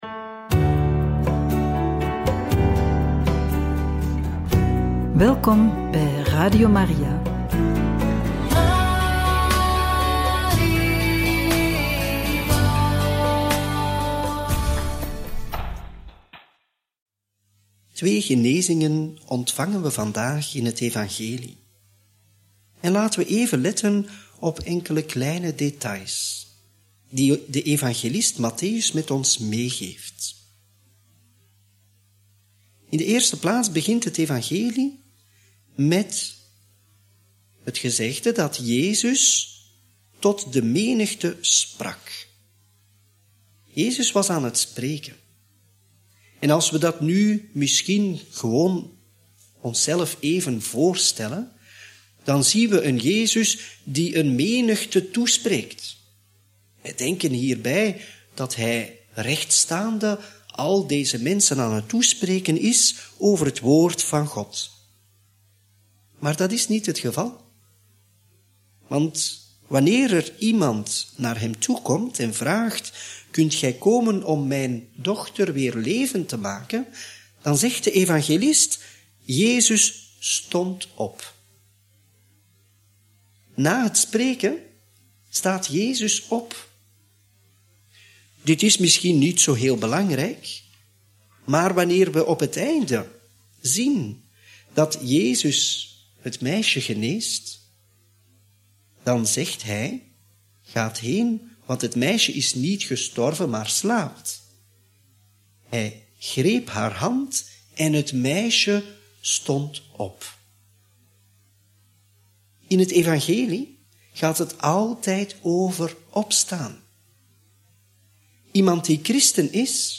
Homilie bij het Evangelie op maandag 8 juli 2024 (Mt. 9, 18-26)